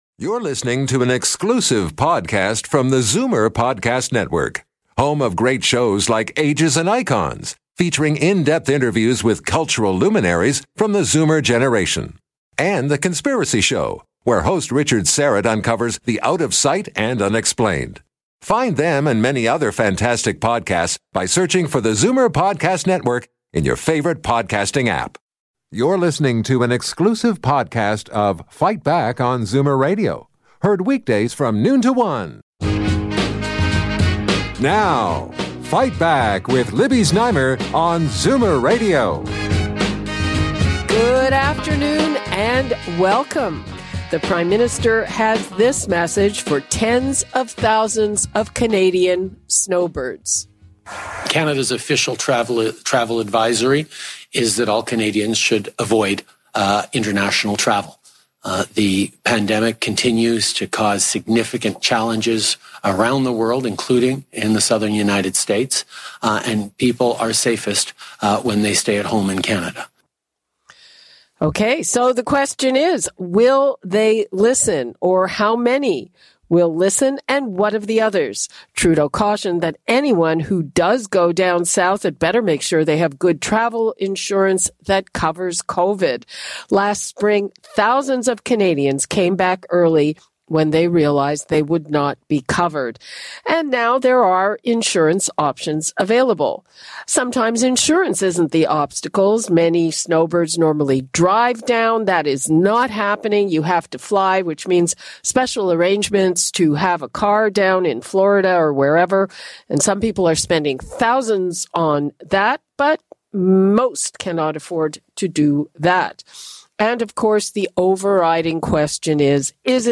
Buy Now Interview on Zoomer Radio!